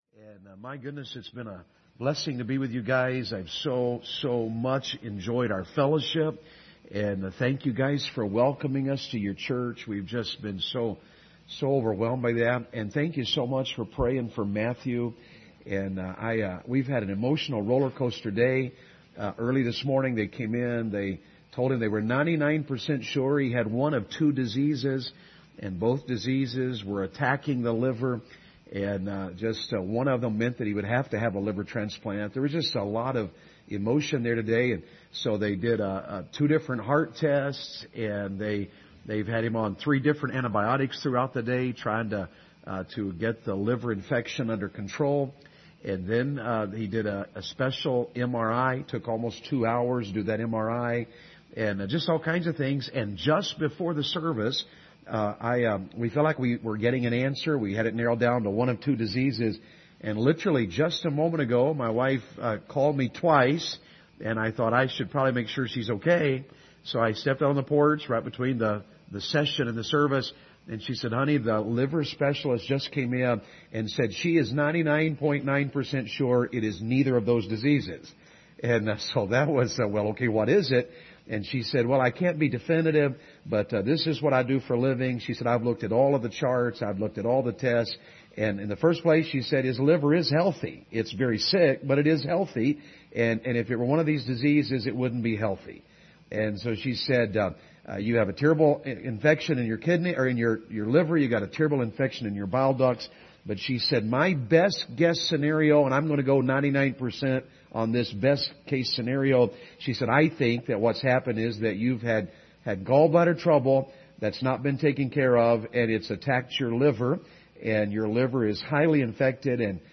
2022 Family Revival Passage: Ephesians 5 Service Type: Revival Service « What in the World Does a Happy Christian Home Look Like?